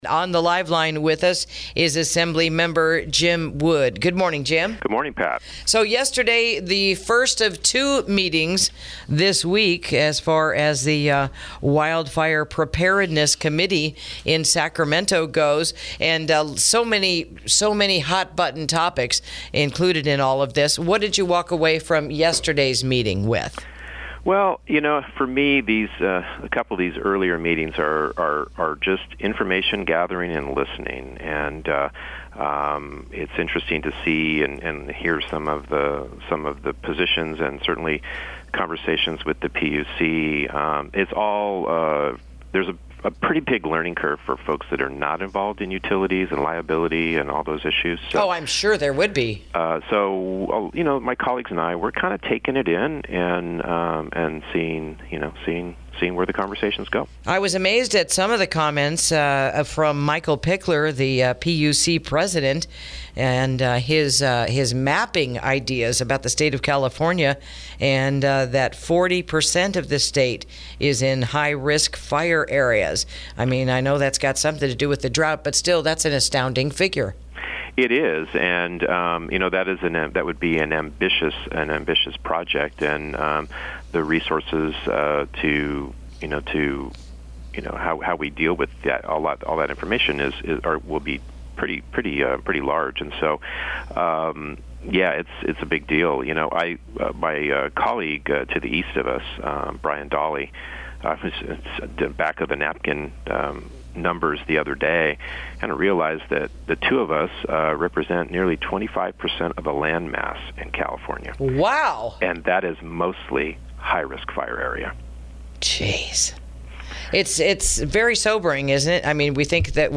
INTERVIEW: What Was Learned at the Recent Wildfire Preparedness and Response Conference